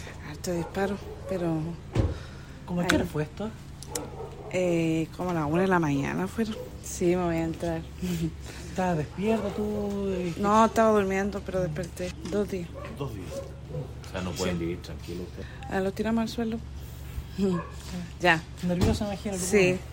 Una de las residentes, en tanto, señaló estar nerviosa por la situación e indicó que debieron lanzarse al suelo para evitar resultar heridos.